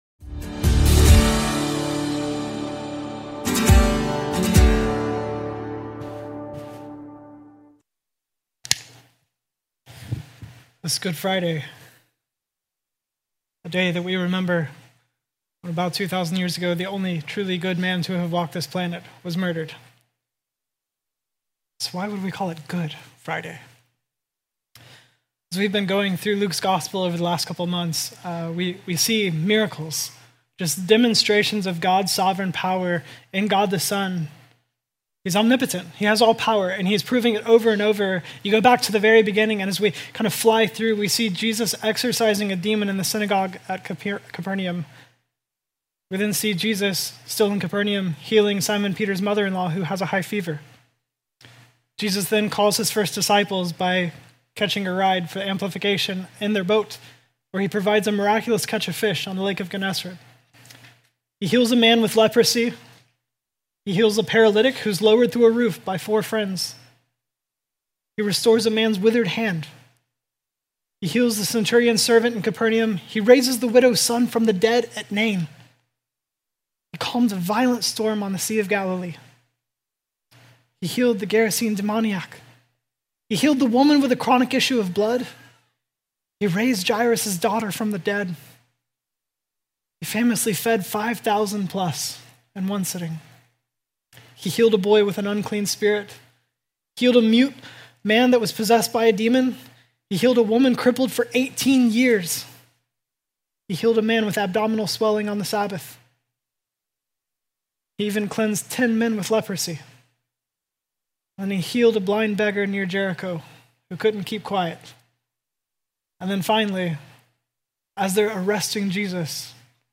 Easter Sunday Passage: Luke 23:44-47 A couple times in Luke’s gospel Jesus explicitly tells someone their sins are forgiven.